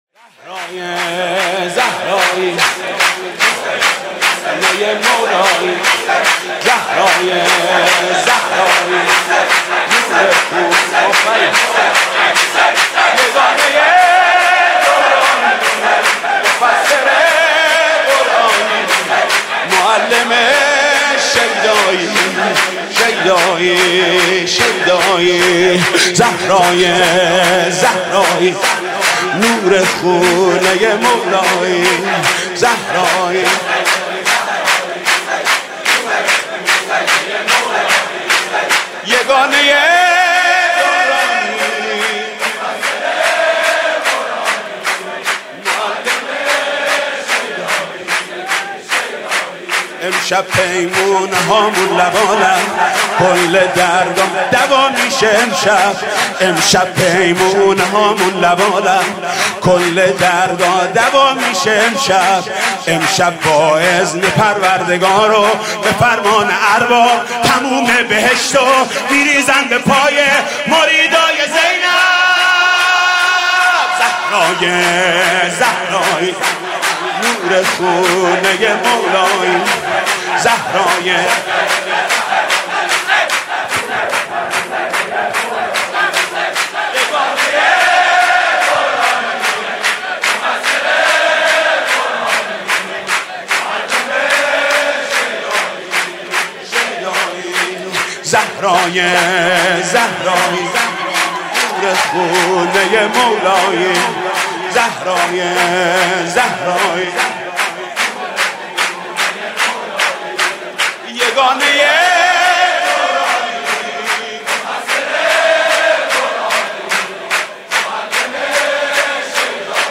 خیمه گاه - عاشقان اهل بیت - مولودی- زهرای زهرایی نور خونه مولایی- حاج محمود کریمی